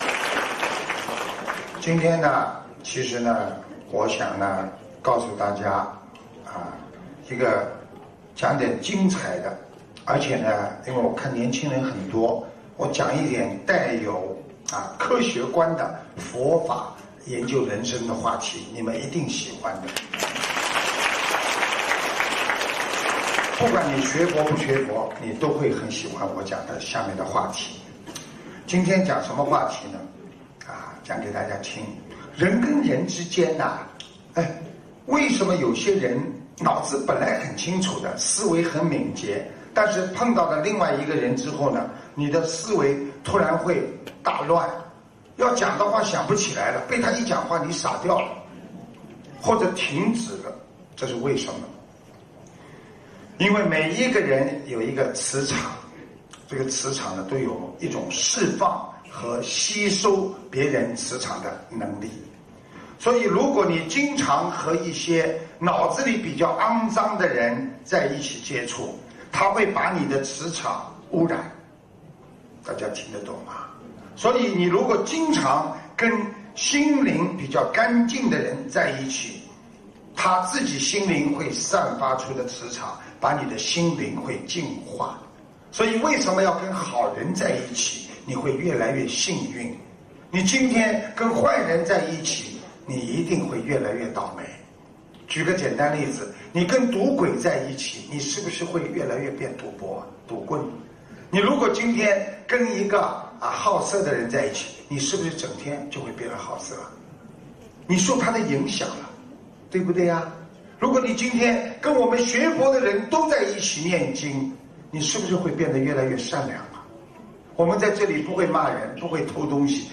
印尼巴淡岛